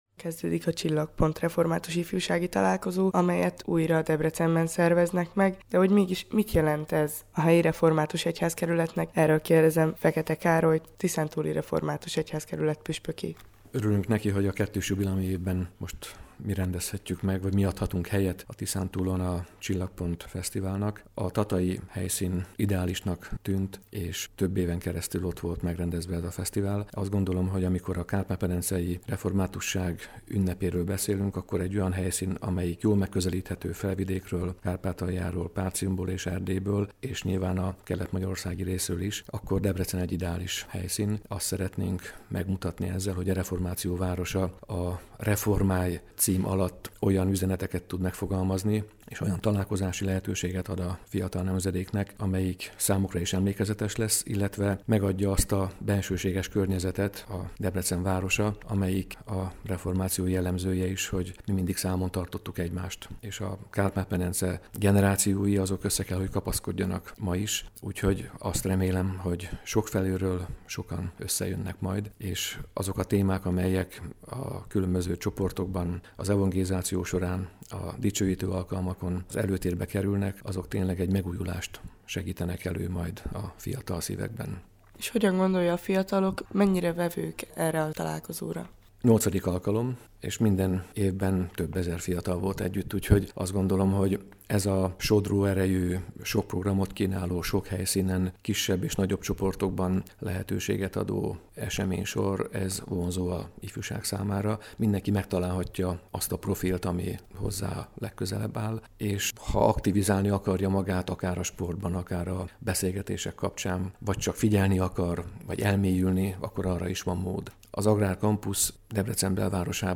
Letöltés Interjú dr. Fekete Károllyal, a Tiszántúli Református Egyházkerület püspökével: